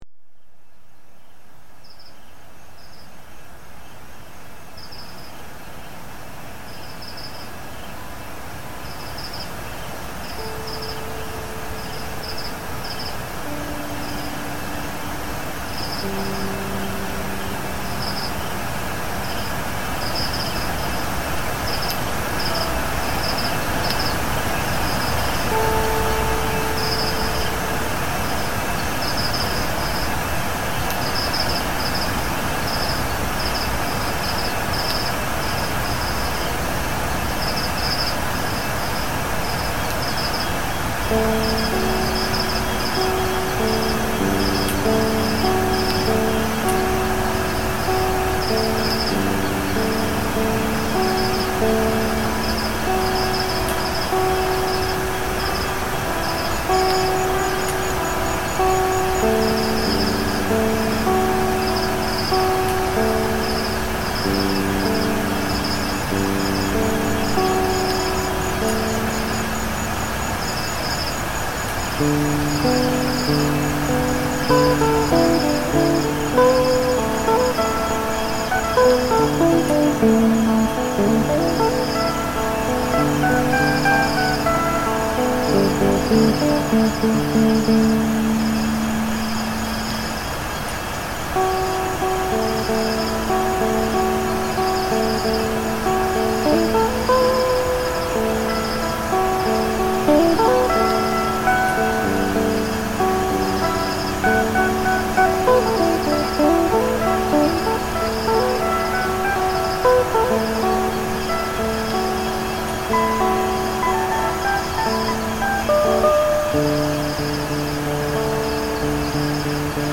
Mara river, Kenya soundscape reimagined